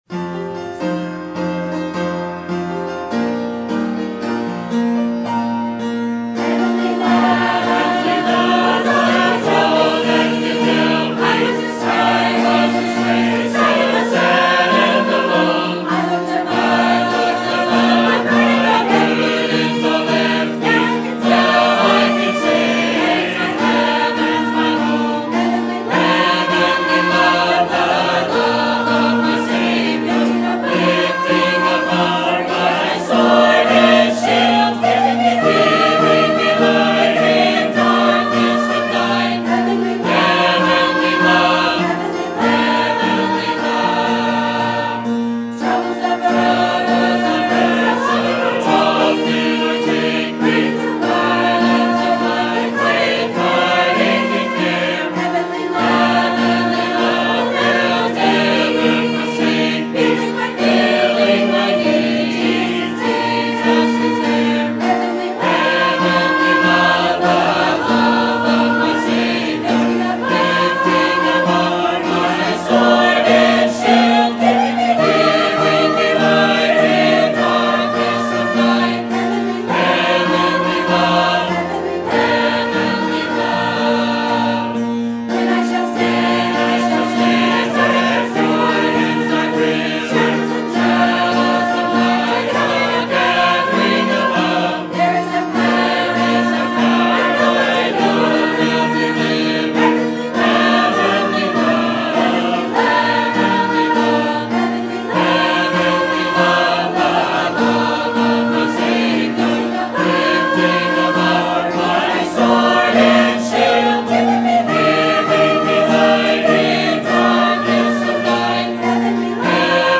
Sermon Type: Series Sermon Audio: Sermon download: Download (28.42 MB) Sermon Tags: John King James Translation Return